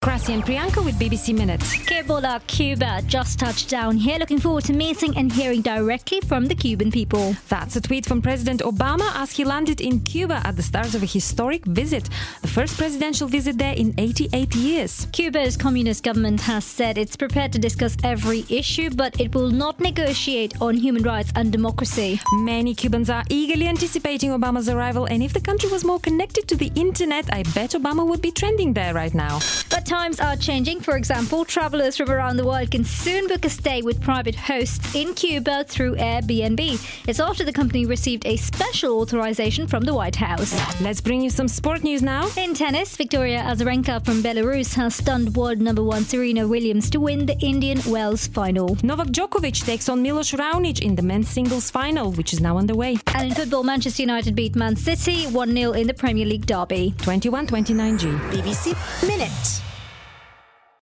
BBC Minute Bulletin